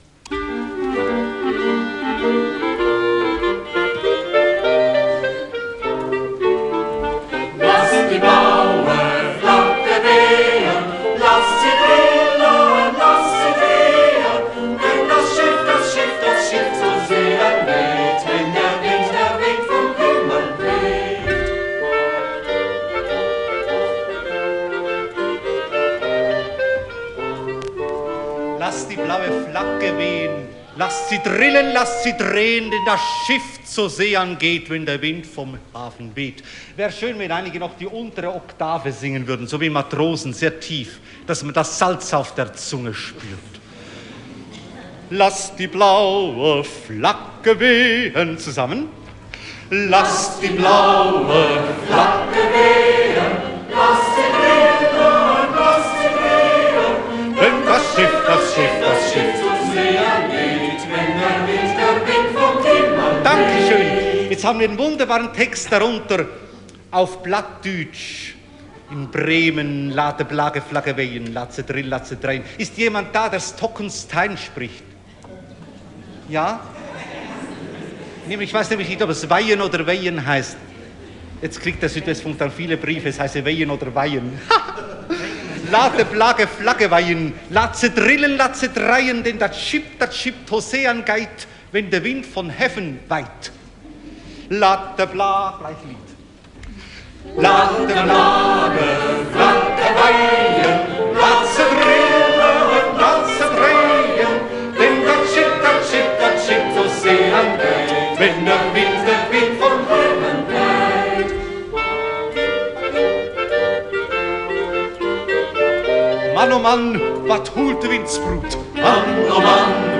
Aufn.: Ostfriesland, 1974-09-01